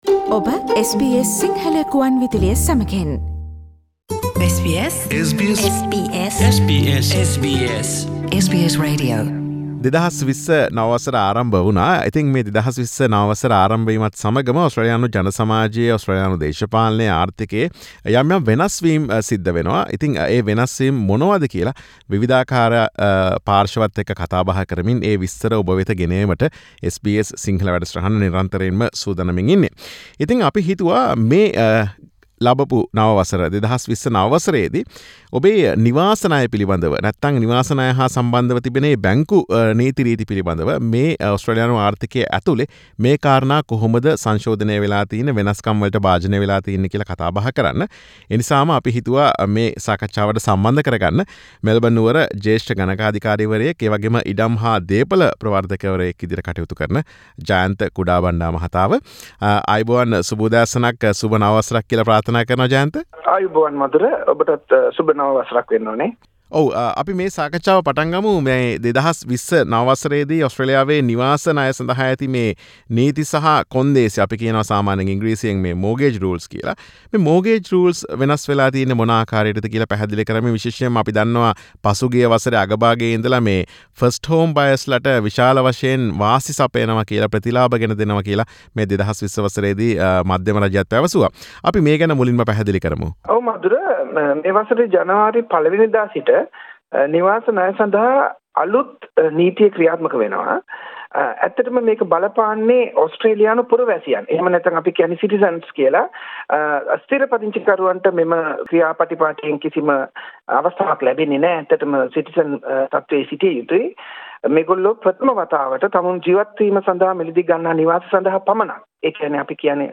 සාකච්ඡාව